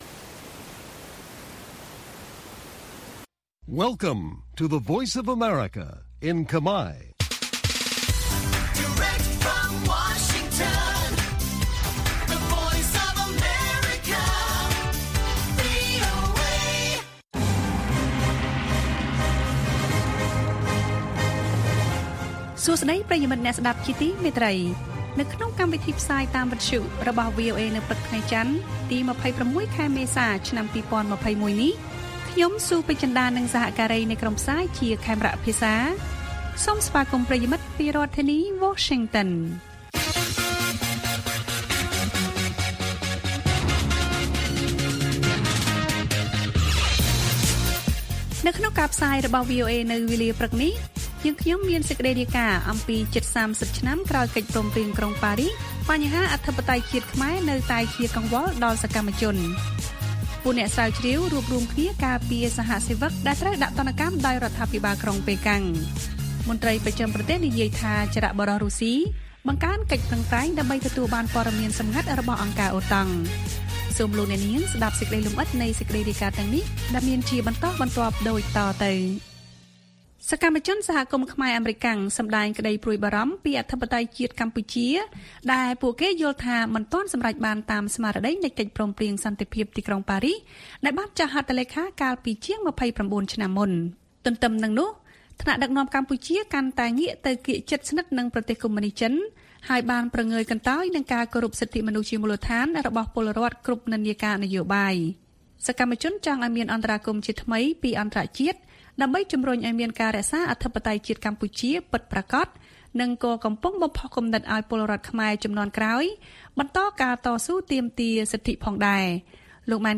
ព័ត៌មានពេលព្រឹក៖ ២៦ មេសា ២០២១